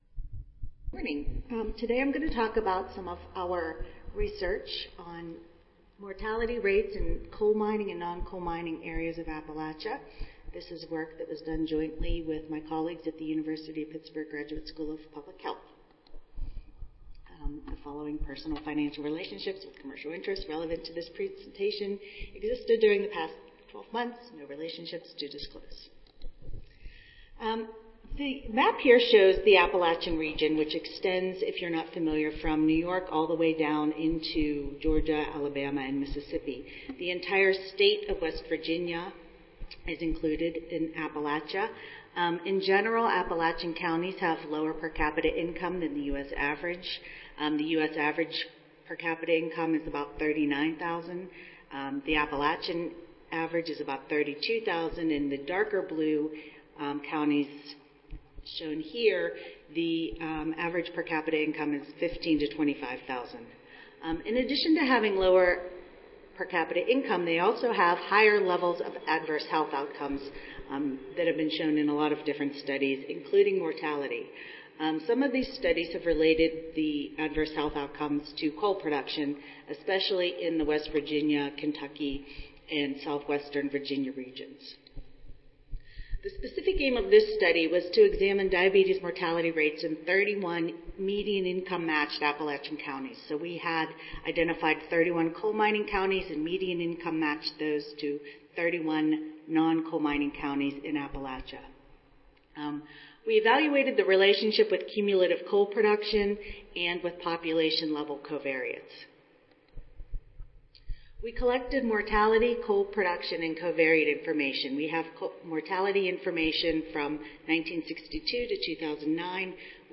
142nd APHA Annual Meeting and Exposition (November 15 - November 19, 2014): Disparities in Diabetes Mellitus Mortality Rates in Coal Mining and Non-Coal Mining Areas of Appalachia